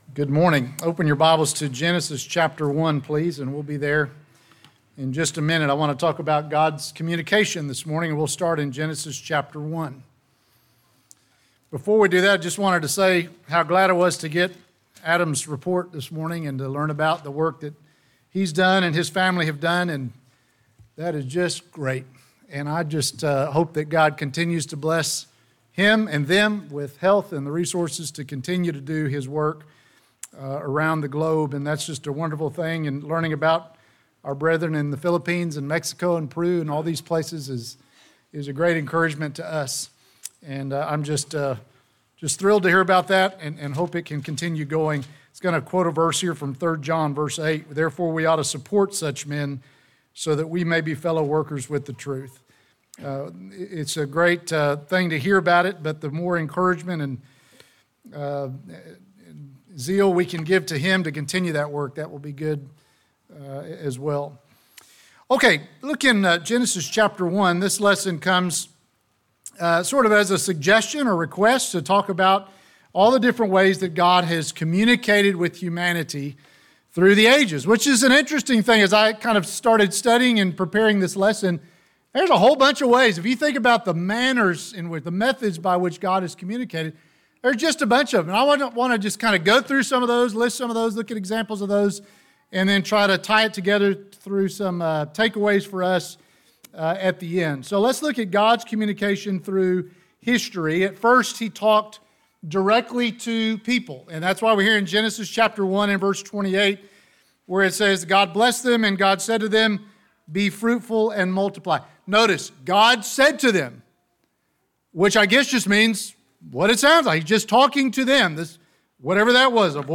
Sermons - Benchley church of Christ